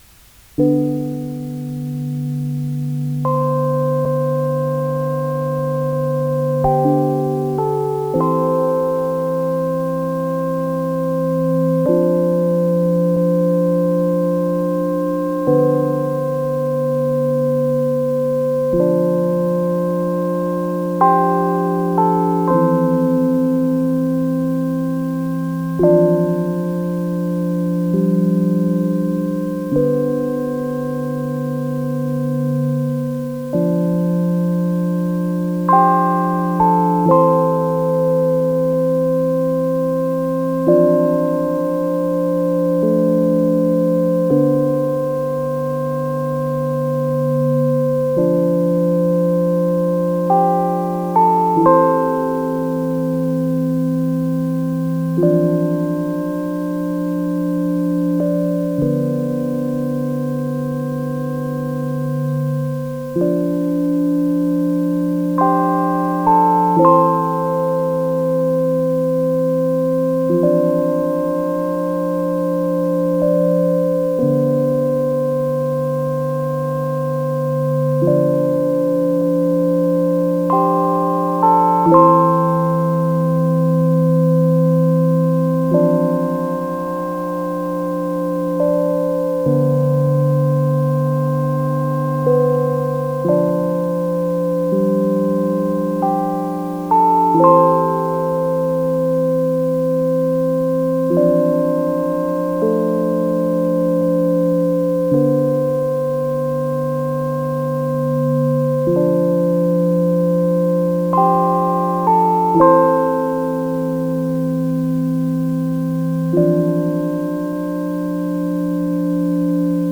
Recording outside of Wakefield, Quebec